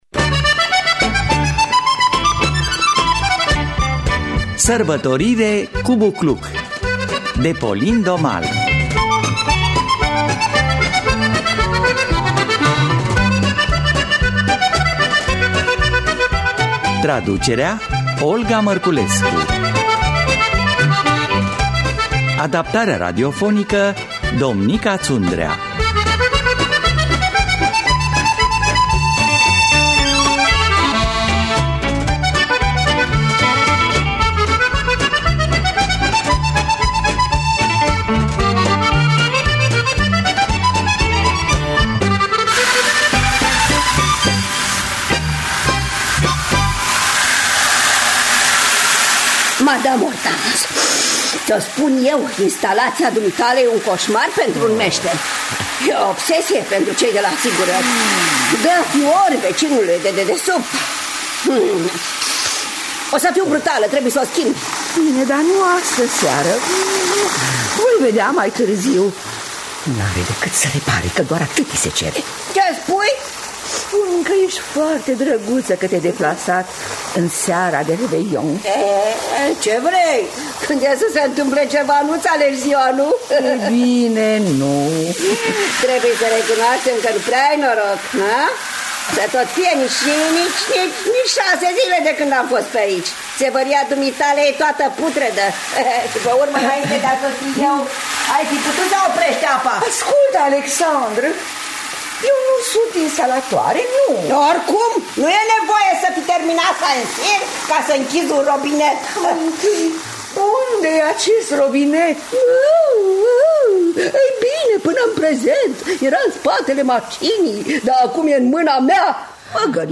Sărbătorire cu bucluc de Pauline Daumale – Teatru Radiofonic Online